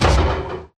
Sound / Minecraft / mob / irongolem / hit2.ogg
hit2.ogg